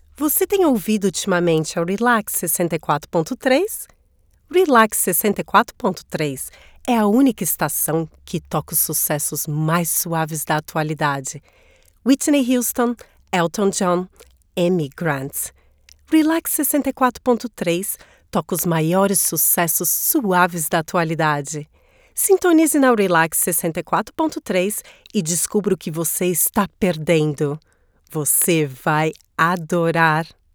Feminino
Radio
Voz Padrão - Grave 00:30